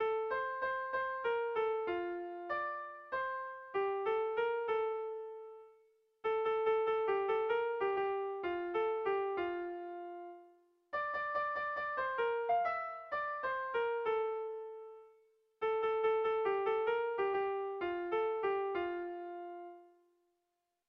Air de bertsos - Voir fiche   Pour savoir plus sur cette section
Kontakizunezkoa
Zortziko txikia (hg) / Lau puntuko txikia (ip)
ABDB